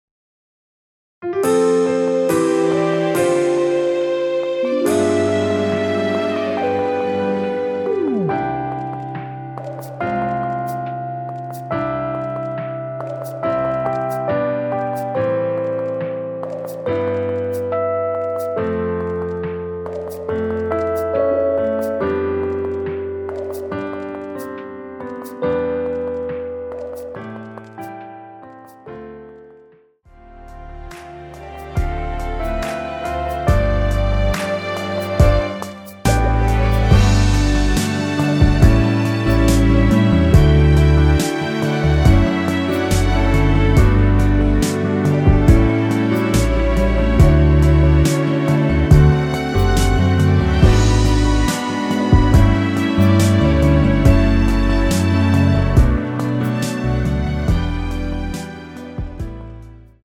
원키에서(-2)내린 MR입니다.
◈ 곡명 옆 (-1)은 반음 내림, (+1)은 반음 올림 입니다.
앞부분30초, 뒷부분30초씩 편집해서 올려 드리고 있습니다.
중간에 음이 끈어지고 다시 나오는 이유는